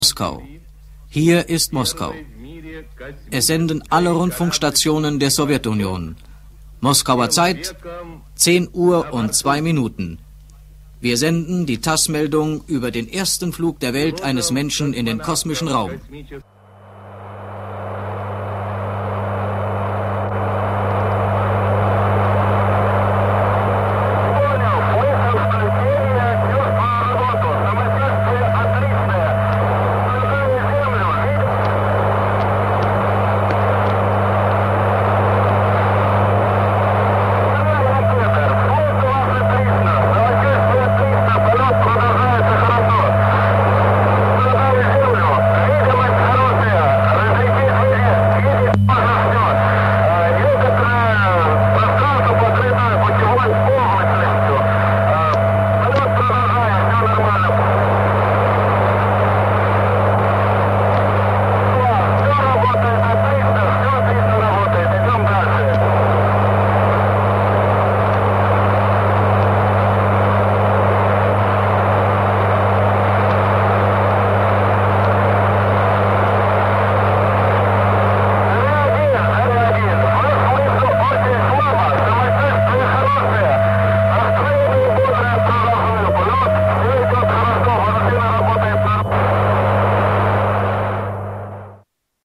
The first communication from the space